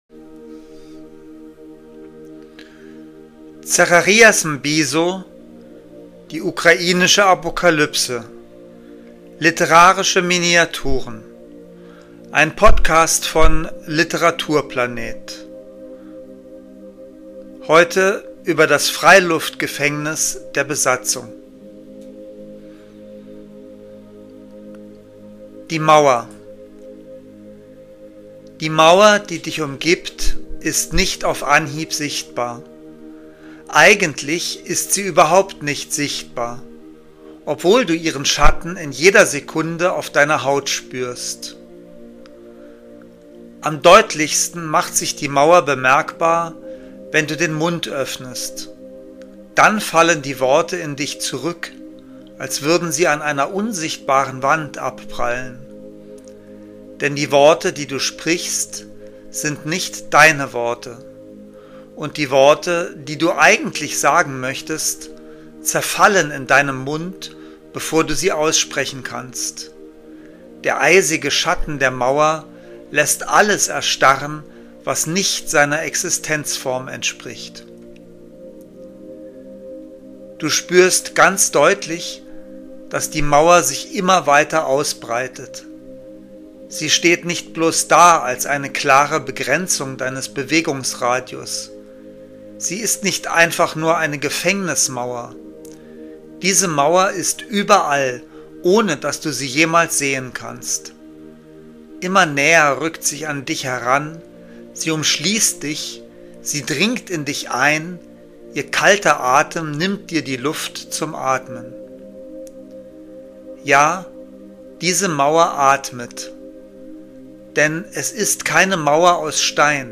Lesungen